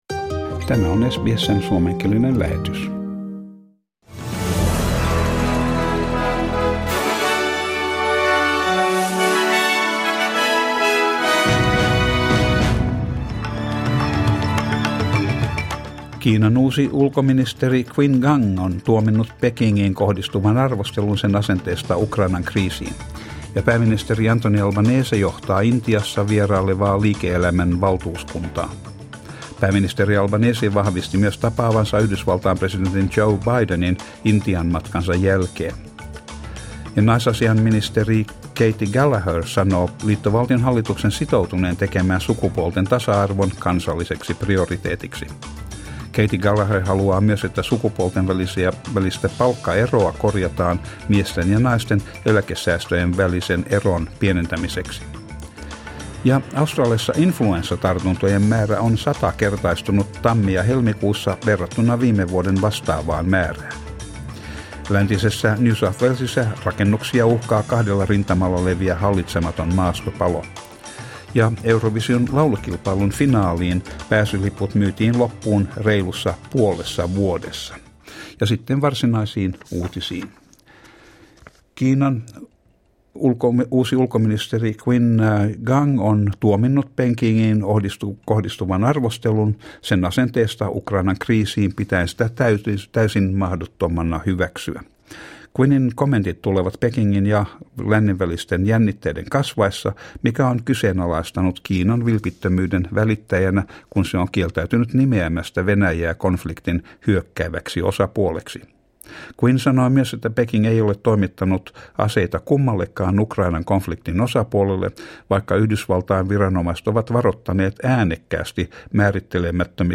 Suomenkieliset uutiset Source: SBS